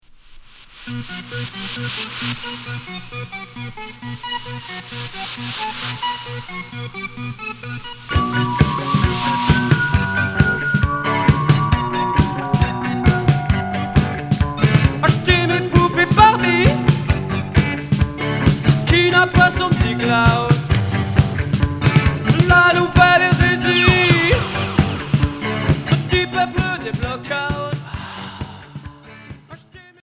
Minimal synth Unique 45t retour à l'accueil